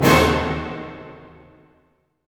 HIT ORCHD05R.wav